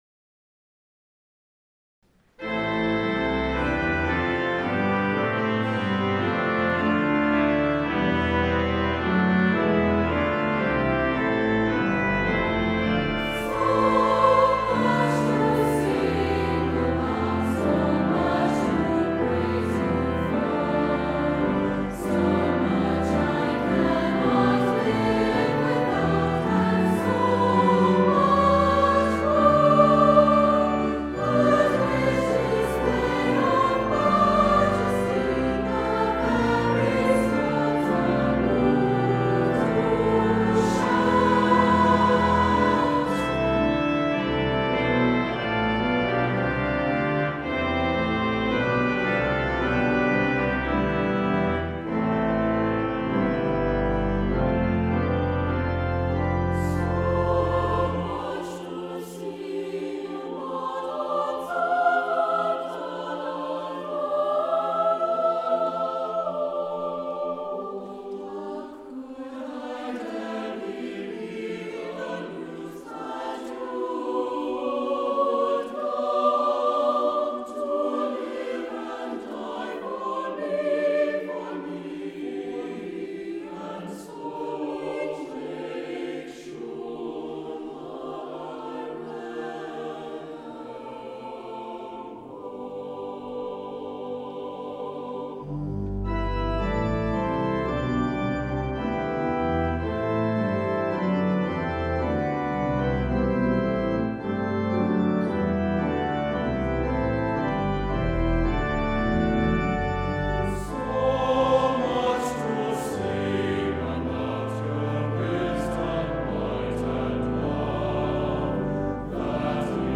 so-much-to-sing-about-2003-performance-at-corinth-reformed.mp3